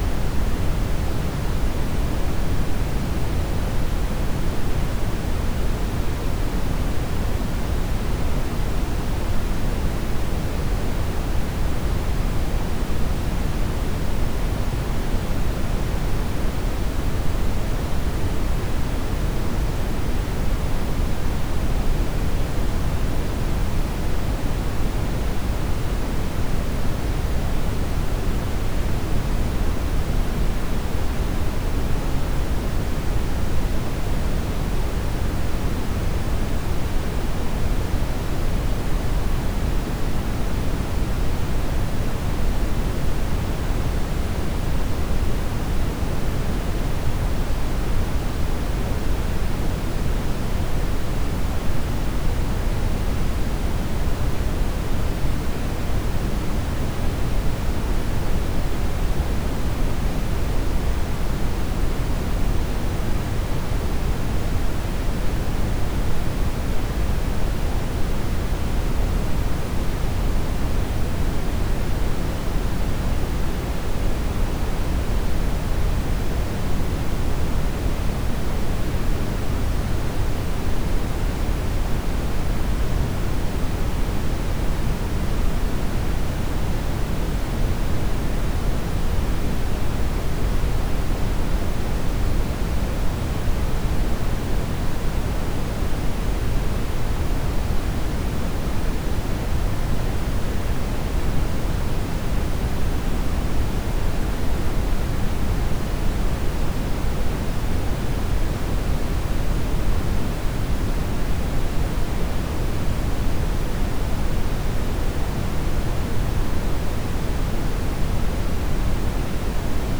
(vi) Brownian noise test signals
A recommended type of test signal is broadband Brownian noise, as this has a frequency spectrum that approximates real musical material[1(p. 3),2(p. 87)].
Brownian Noise MONO -23 LUFS 48 kHz.wav